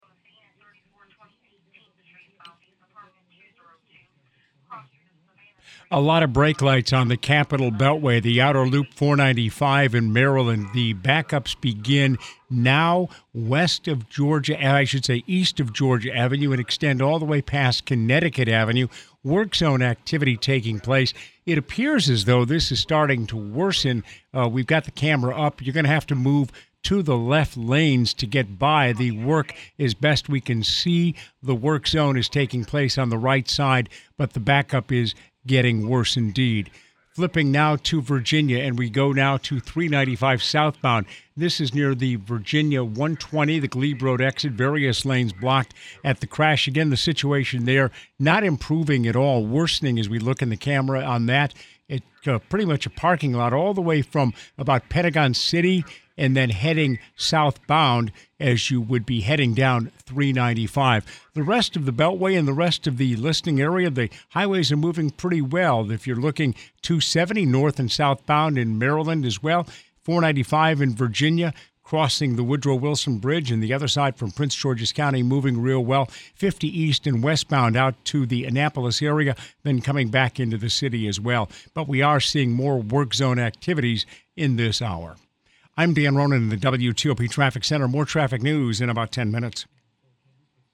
Recent Traffic Report.